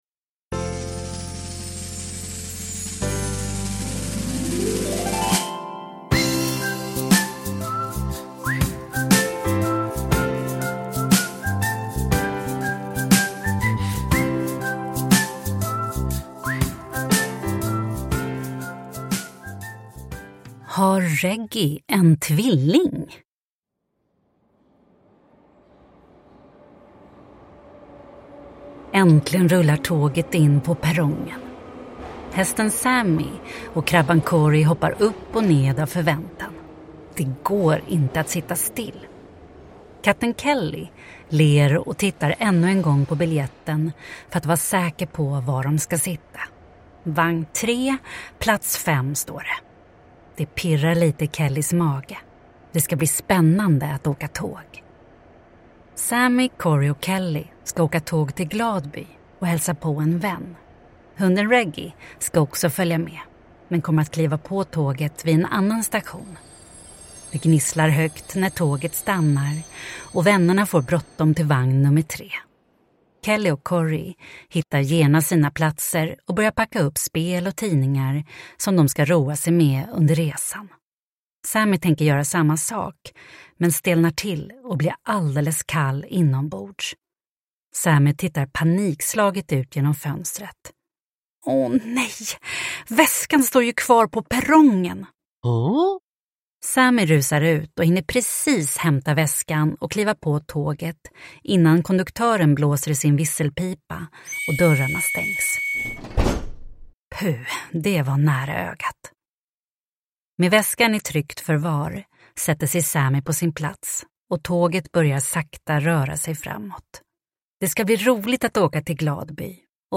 Har Reggy en tvilling? – Ljudbok – Laddas ner